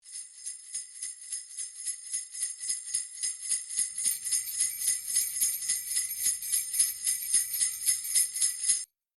Sleigh Bells
Sleigh Bells is a free music sound effect available for download in MP3 format.
yt_ufudbb82Jss_sleigh_bells.mp3